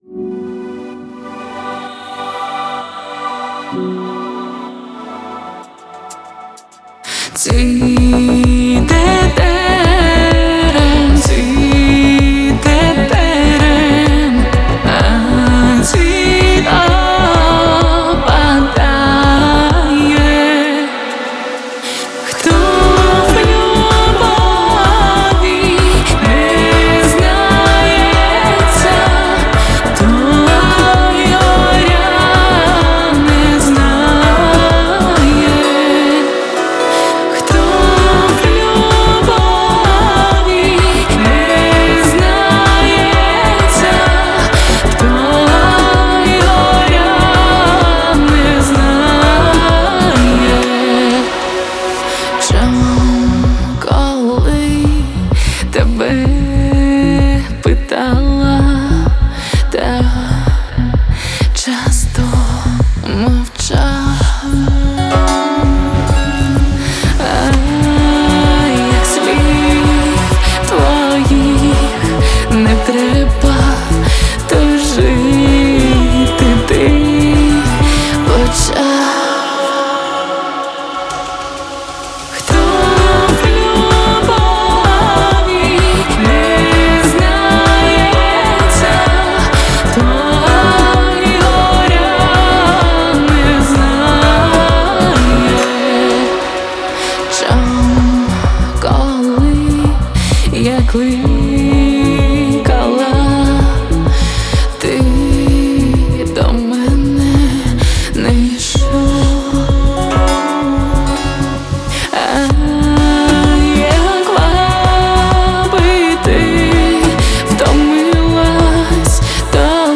• Жанр: Українська музика